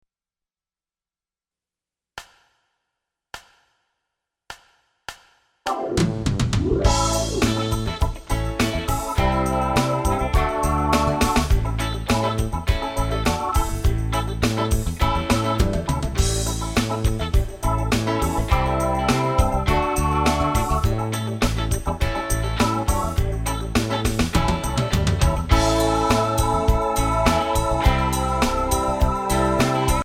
Voicing: Guitar and m